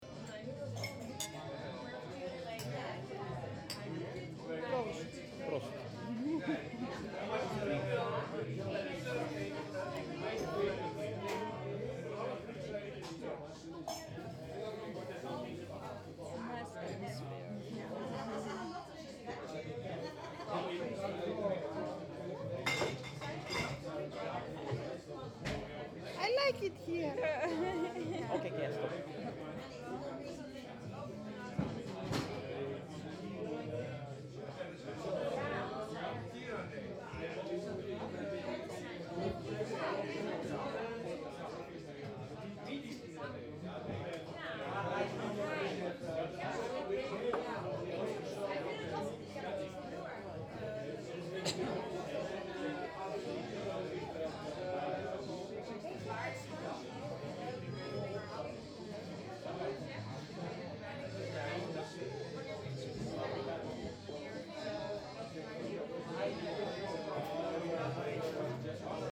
Prête moi tes yeux : Au pub
08/03/2015 18:00 Au pub Nous buvons une lourde bière dans un pub. L’ambiance y est chaleureuse. Les touristes se mêlent aux habitués.